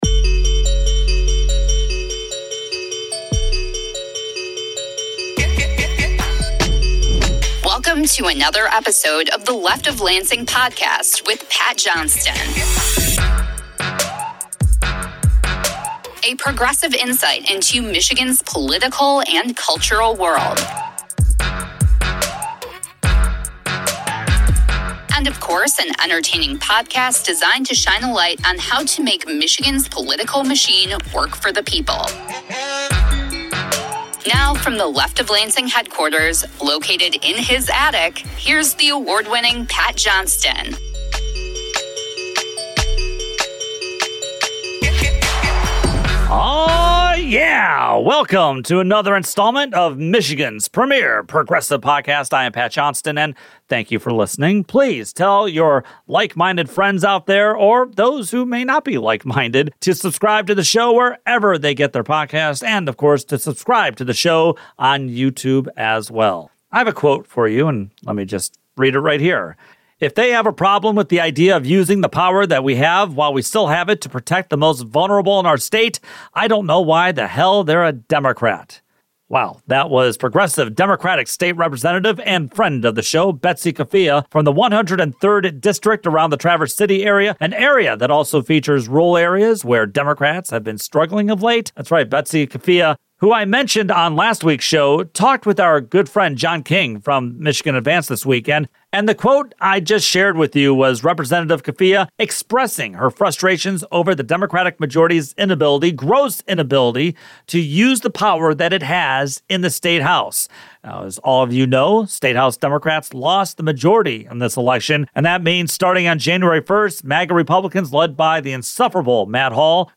Progressive members are growing frustrated with Democratic leadership's constant moves to suppress good, progressive bills in favor of bills helping the corporate donor base. 8:51-39:30: Rep. Emily Dievendorf Interview Democratic State Rep. Emily Dievendorf (D-Lansing) joins the show this week.